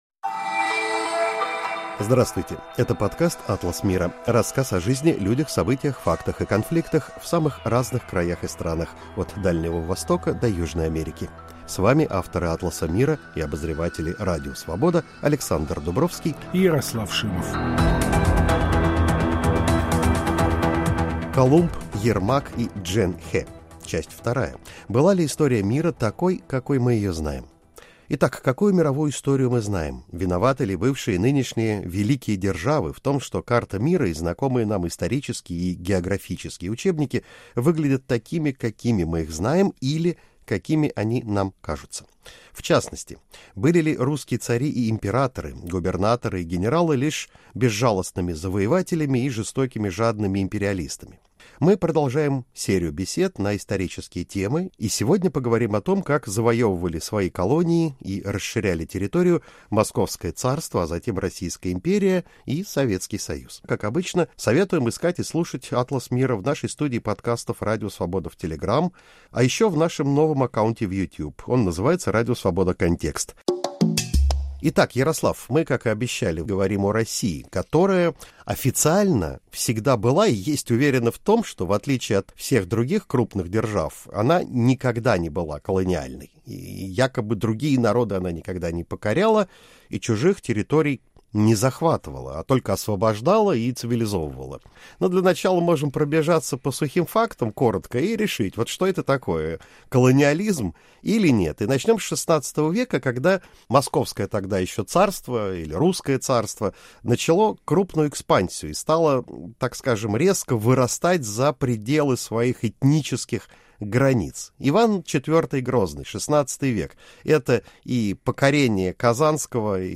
Мы продолжаем цикл бесед о переоценках истории. В новом выпуске – беседа о том, как завоевывали свои колонии и расширяли территорию Московское царство, а затем Российская империя и СССР.